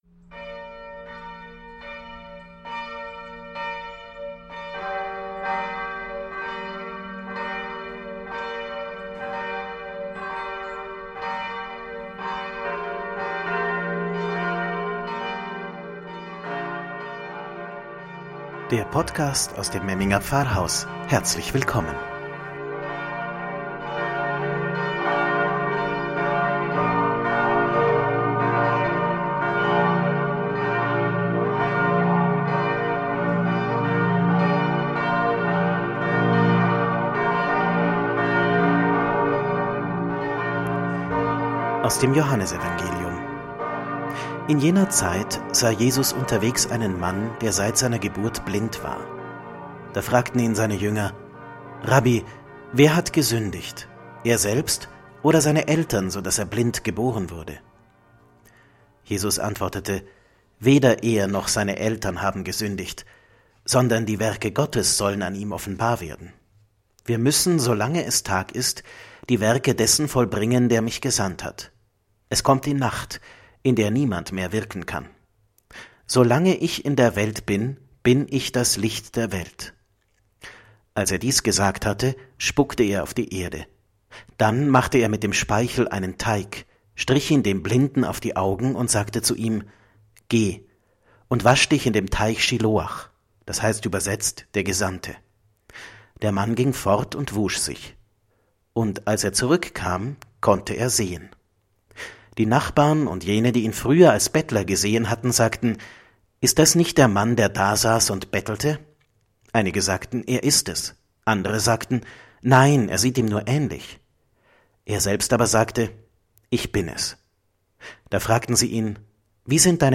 „Wort zum Sonntag“ aus dem Memminger Pfarrhaus – Podcast zum 4. Fastensonntag